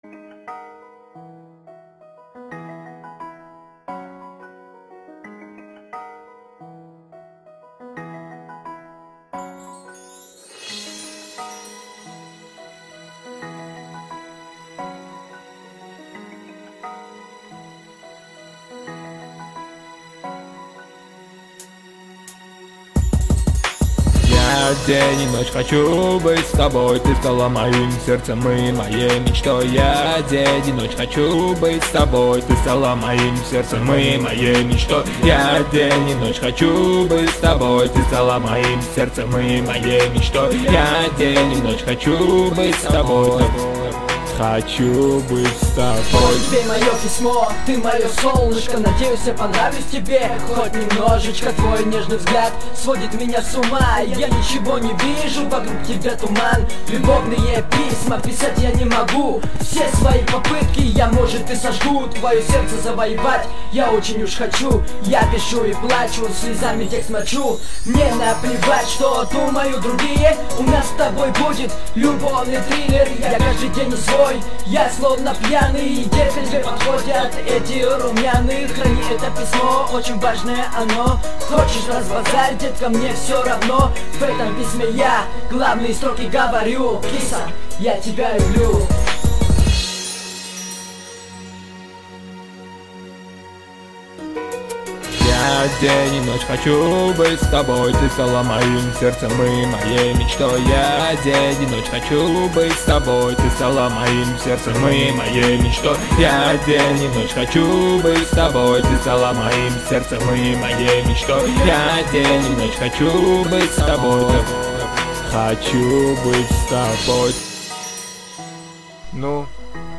мой рэп